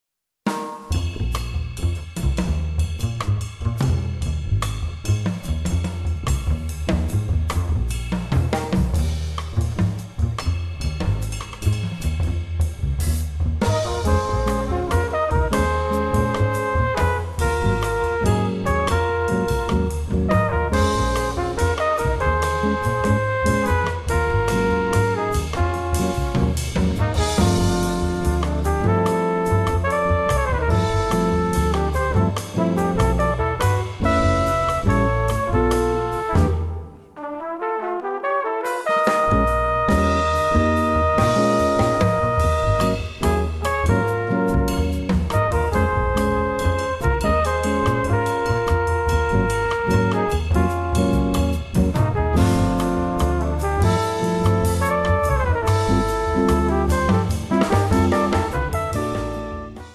trumpet, flugelhorn